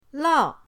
lao4.mp3